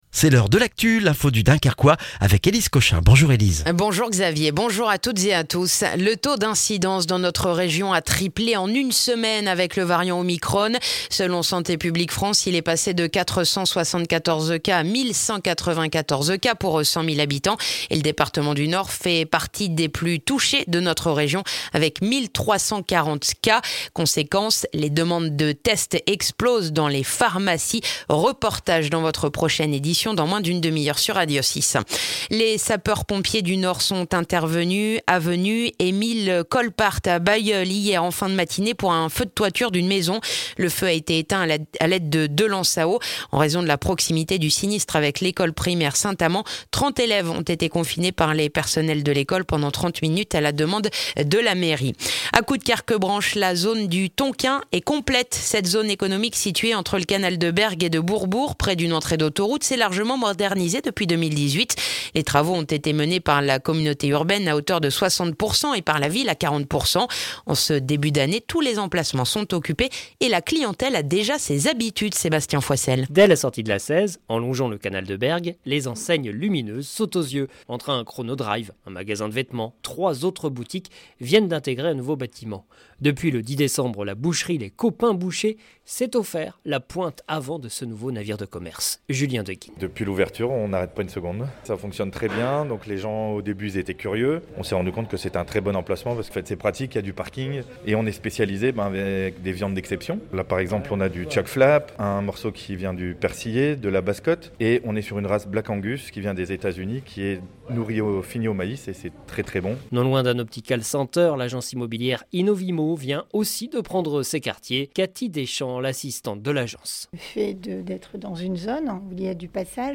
Le journal du mercredi 5 janvier dans le dunkerquois